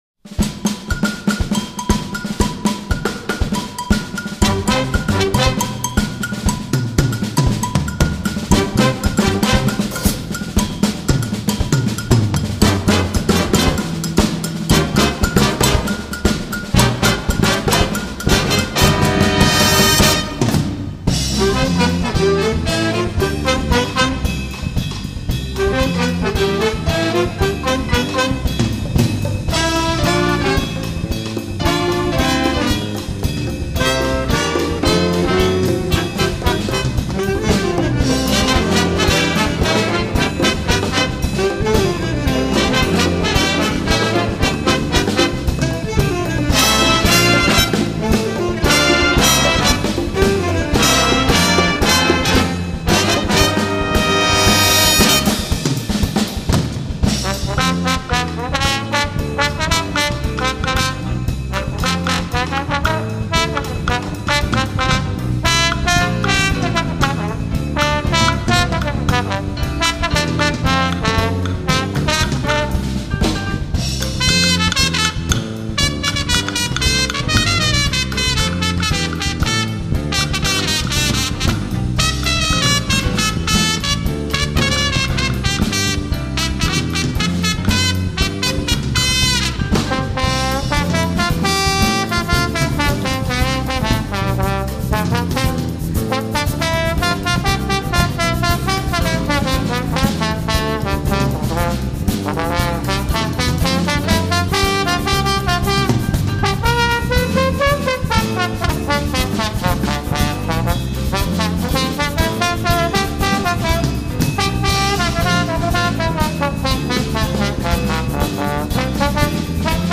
J A Z Z   B A N D S